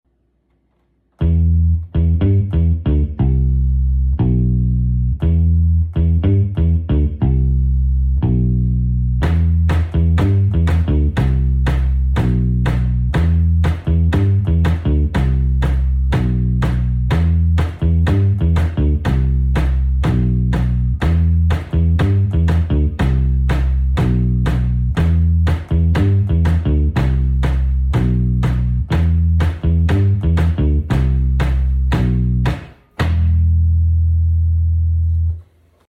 pop & rock songs